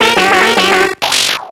Cri de Soporifik dans Pokémon X et Y.